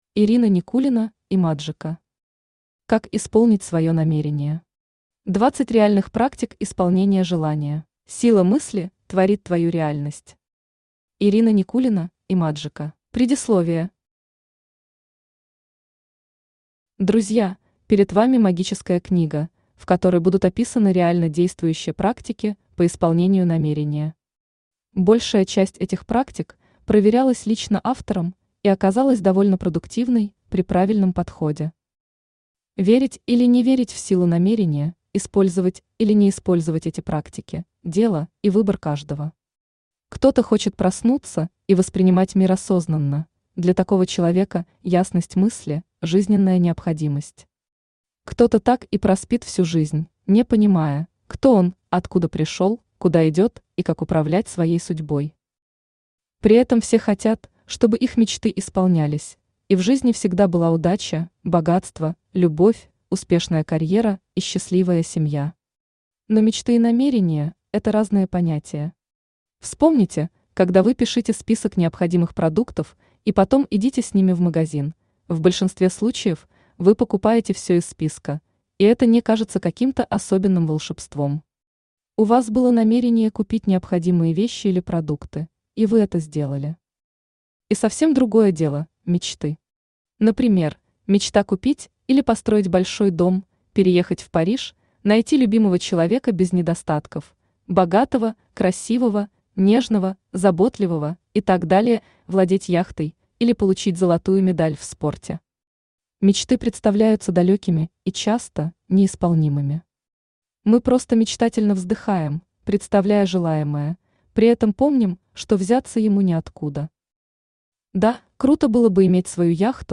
Аудиокнига Как исполнить своё намерение. 20 реальных практик исполнения желания | Библиотека аудиокниг
Aудиокнига Как исполнить своё намерение. 20 реальных практик исполнения желания Автор Ирина Никулина Имаджика Читает аудиокнигу Авточтец ЛитРес.